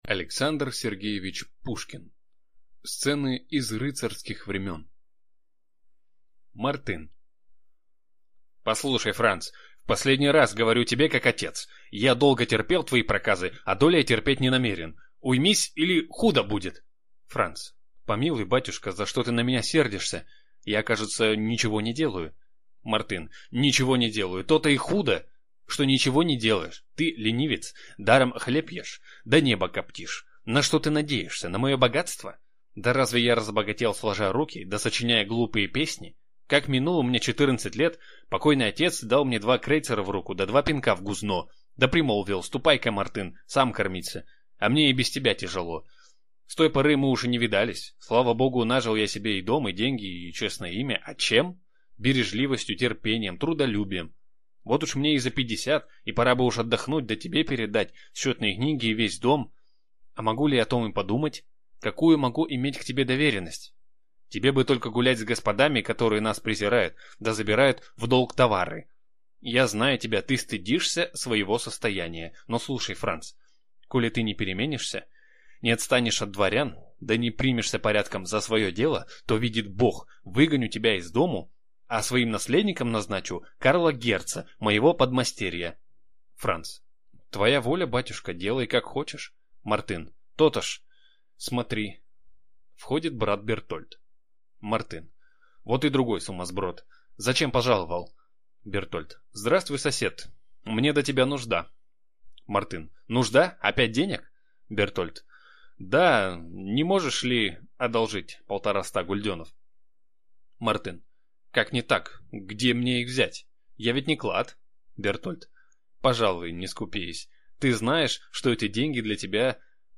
Аудиокнига Сцены из рыцарских времен | Библиотека аудиокниг